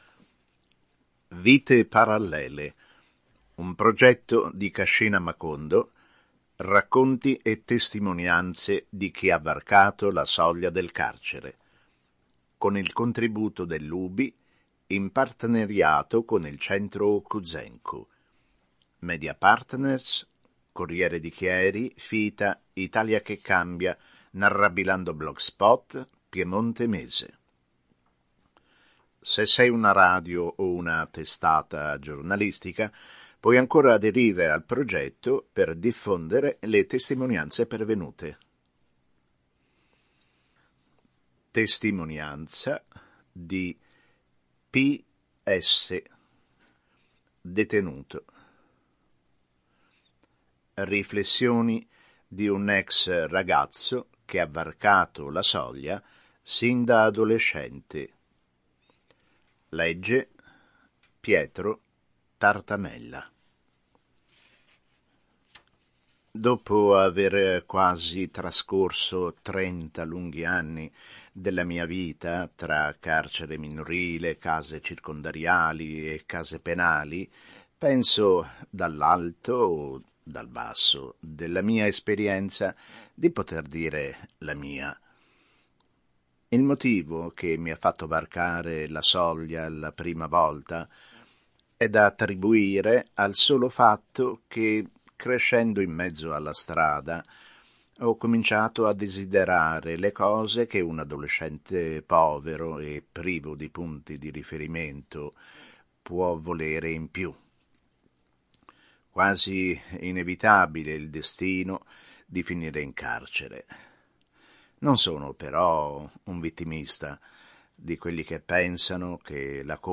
TESTIMONIANZA N° 10